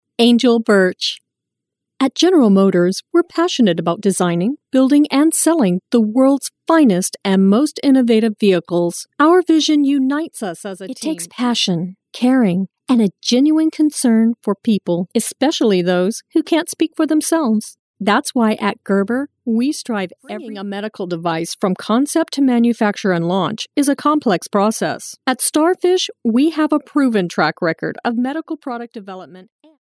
Voice Intonations: Bright, Energetic, Professional, Calming, Adult, Millennial, Child, Senior
englisch (us)
Sprechprobe: Industrie (Muttersprache):